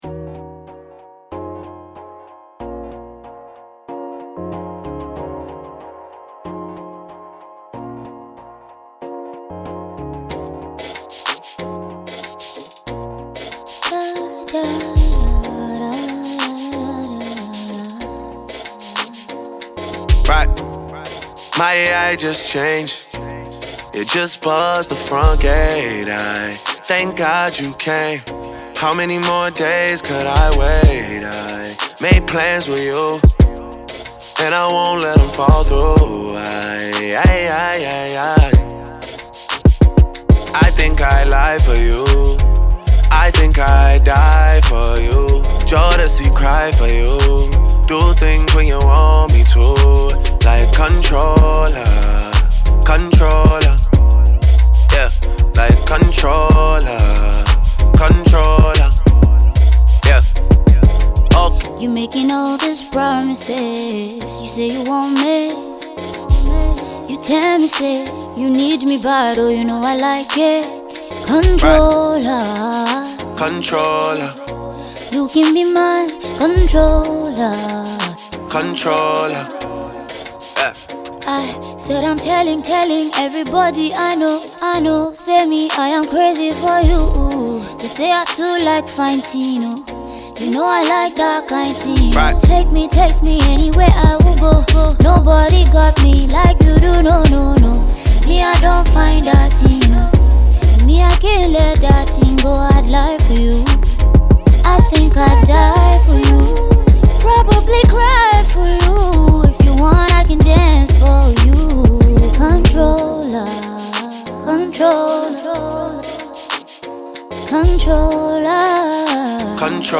Afro pop artist